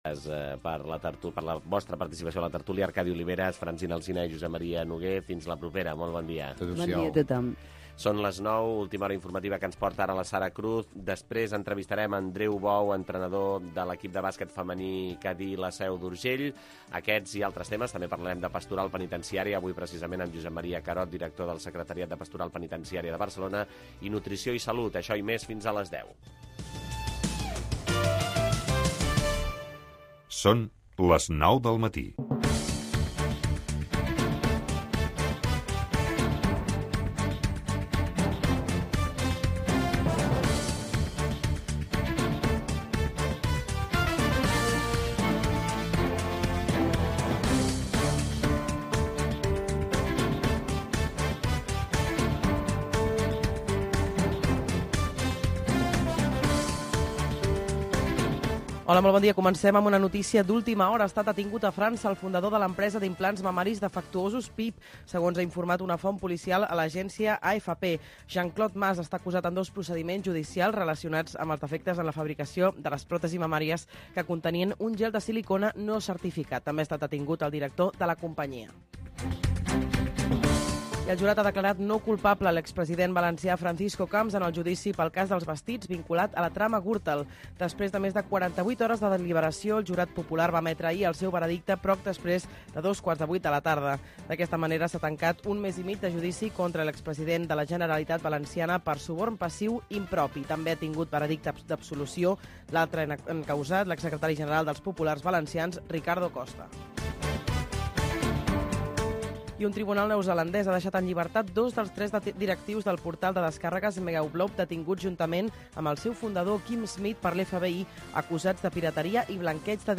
El primer cafè. Informació, actualitat, espais, 2 hores d'un magazin matinal